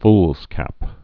(flzkăp)